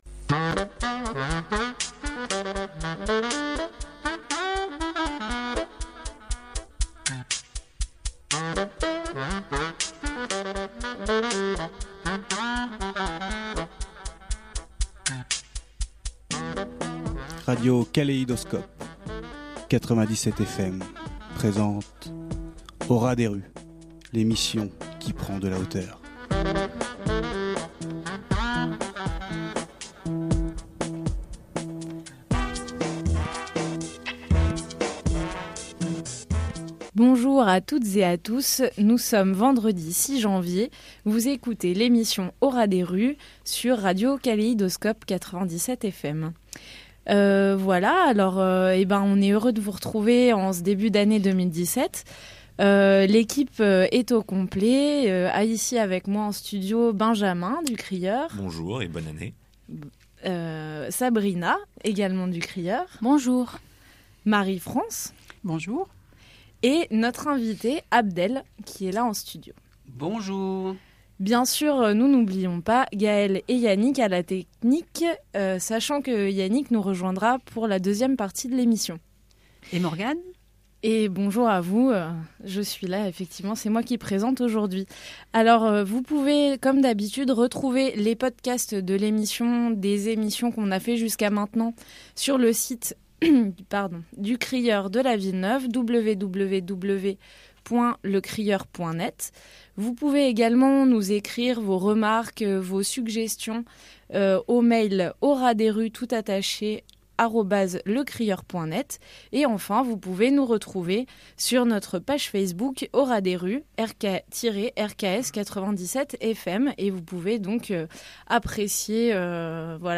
Au ras des rues est une émission hebdomadaire sur Radio Kaléidoscope (97 fm) qui s’intéresse à l’actualité des quartiers sud de Grenoble et de l’agglo : Villeneuve, Village Olympique, Mistral, Abbaye-Jouhaux, Ville Neuve d’Échirolles…